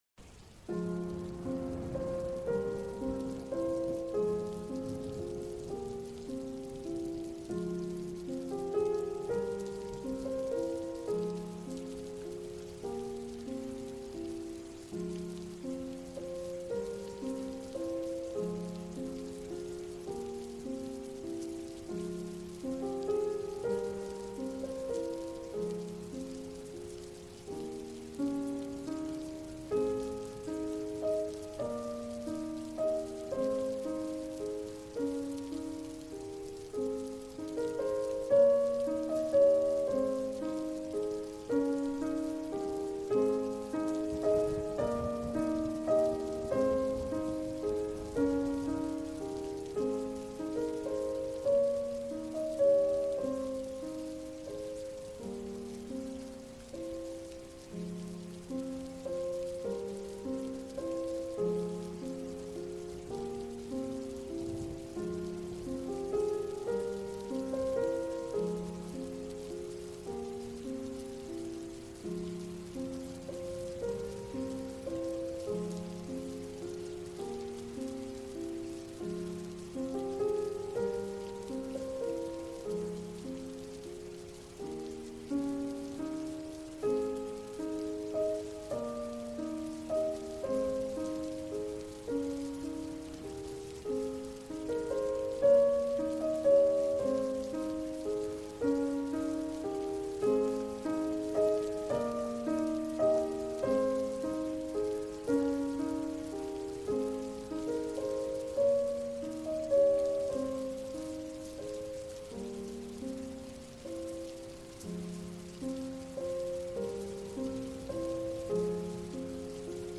Paris Naturelle : Pluie du Soir